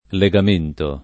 legamento [ le g am % nto ]